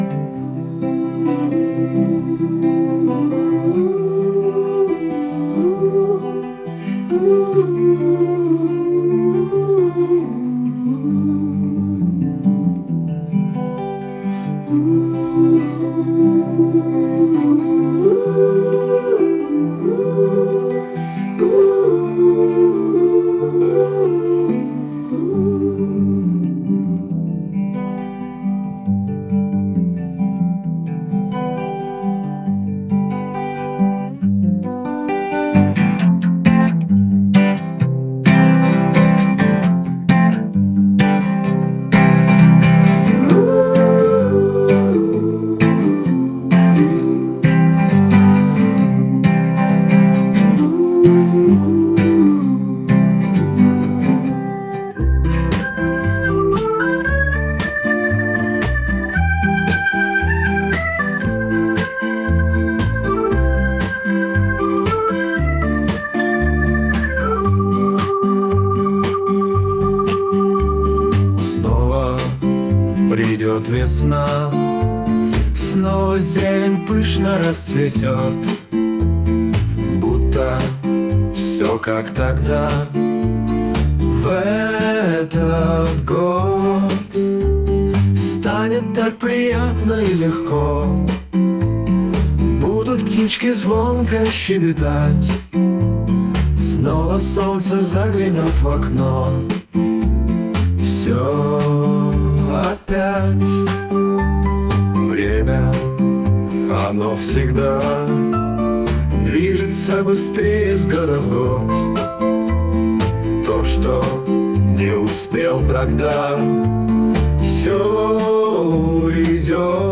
• 4: Рок